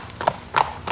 Horse Walk Sound Effect
Download a high-quality horse walk sound effect.
horse-walk.mp3